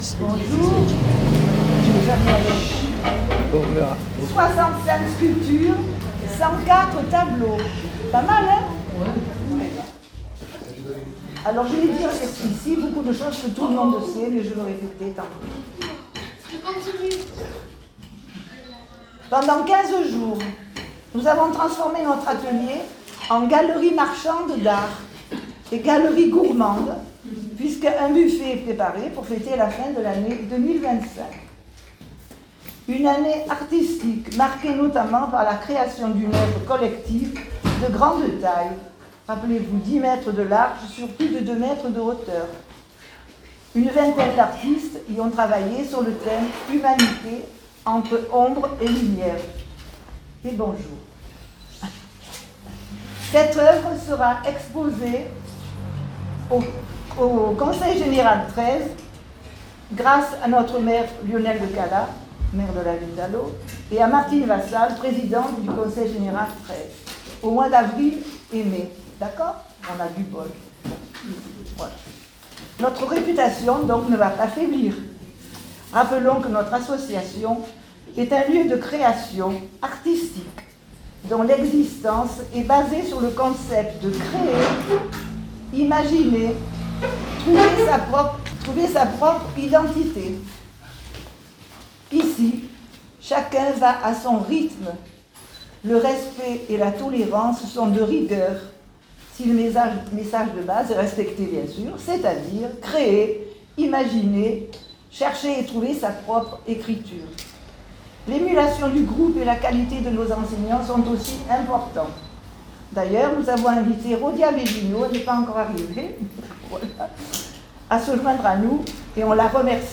un ENREGISTREMENT fait le soir du vernissage
(1) Pour le "fun" - Transcription par l'intelligence artificielle du discours enregistré par téléphone...